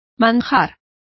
Complete with pronunciation of the translation of delicacy.